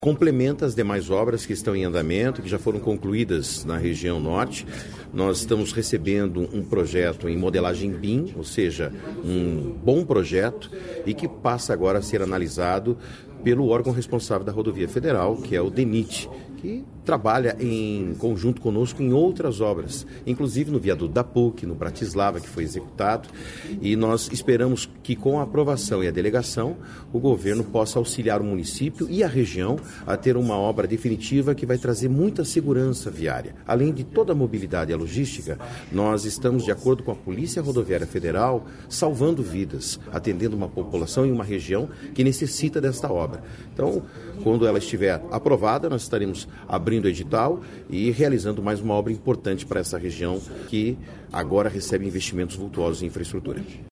Sonora do secretário de Infraestrutura e Logística, Sandro Alex, sobre o projeto executivo do Viaduto Esperança